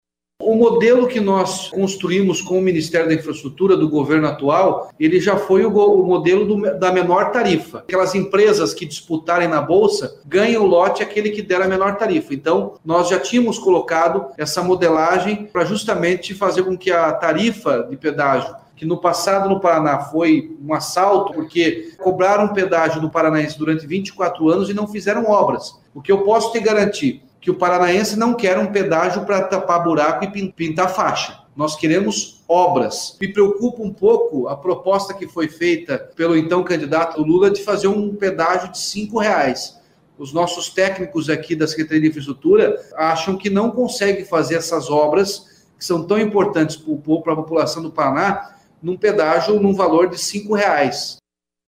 No entanto, em entrevista à TV Estadão, o atual governador do Paraná, Carlos Massa Ratinho Junior (PSD) disse que teme pelo que será feito com o pedágio no estado.
Player Ouça Governador Ratinho Junior